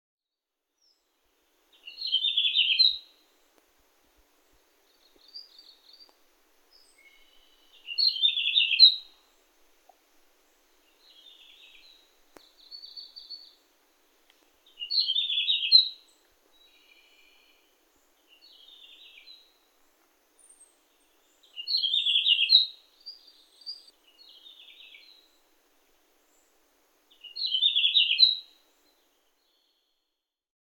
１　ルリビタキ【瑠璃鶲】　全長約14cm
夏の亜高山帯での主役といえばルリビタキ、「ヒッチョ、チョリチョリ・・・」と軽やかによくとおる声でさえずっていた。
【録音1】 　2025年8月5日　日光白根山
さえずりが力強く、はっきりしている個体の声